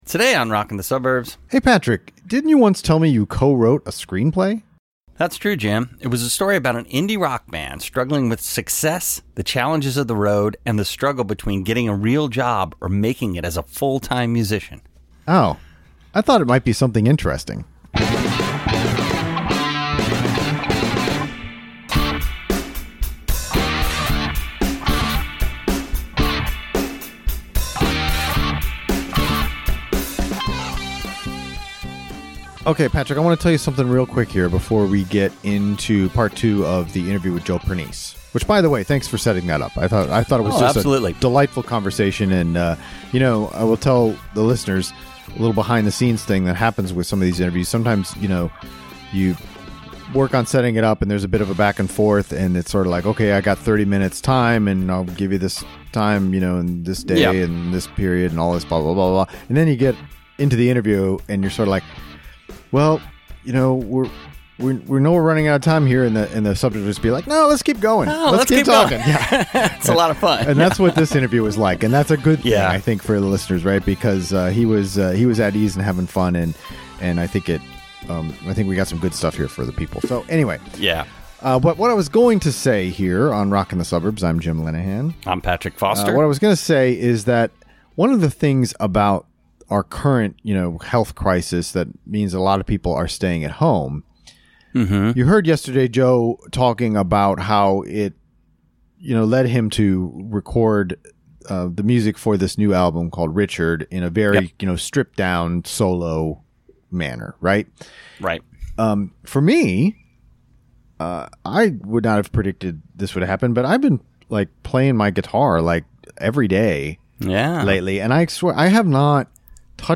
Joe Pernice - Interview, Part 2
Part 2 of our interview with Joe Pernice, solo artist and also of Pernice Brothers and Scud Mountain Boys.